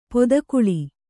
♪ podakuḷi